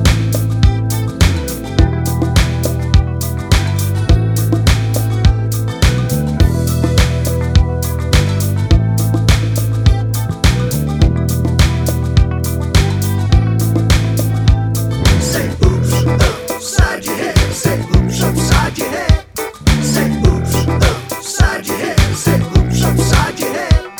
no chorus vocal Pop (1980s) 3:30 Buy £1.50